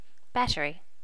Initial check in of the sounds for the notify plugin.
battery.wav